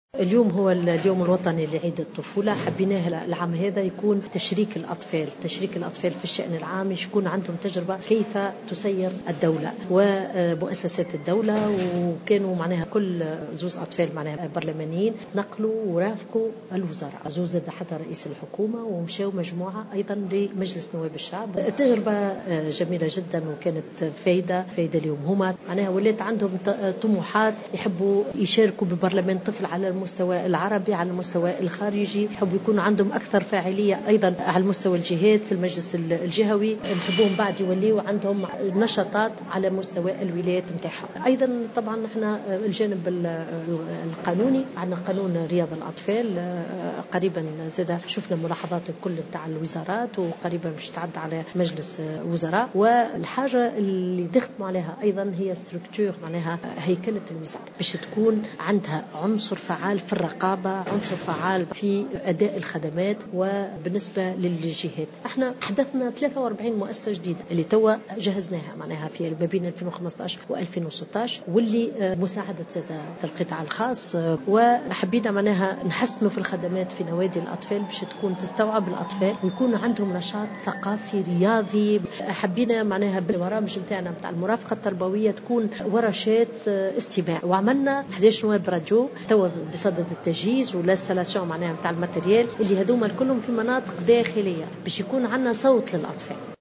أكدت وزيرة المرأة والاسرة والطفولة سميرة مرعي اليوم على هامش الاحتفال بالعيد الوطني للطفولة في الحمامات أنه من المنتظر أن يتم عرض مشروع قانون يتعلّق برياض الأطفال قريبا على مجلس وزاري.